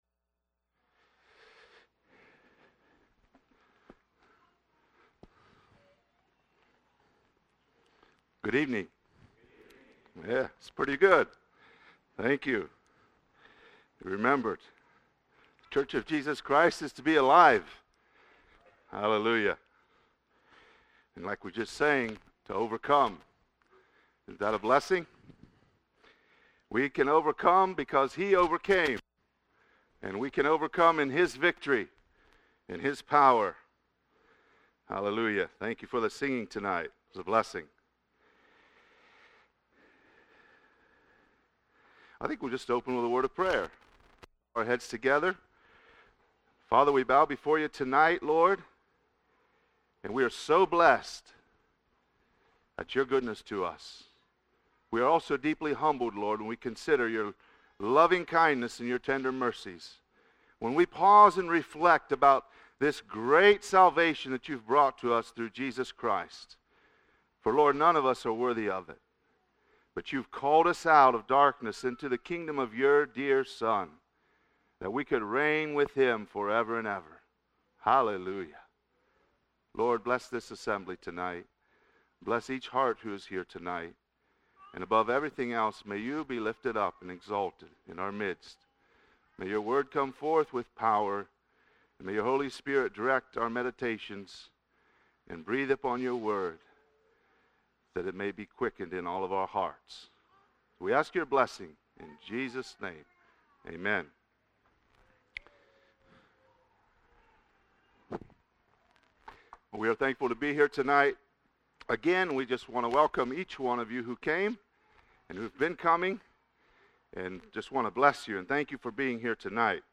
Series Tent Meetings 2022